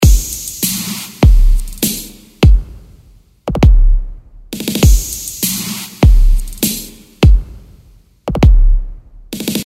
In Engine 2 habe ich nur Pad 1 mit dem Sound FM Blip B 1 aus dem Expansion-Set Analogue Mayhem geladen und Automationsspuren für Panorama, Filter Cutoff und Send 1 angelegt.
Das „Rohmaterial“ ohne diese Bearbeitungen …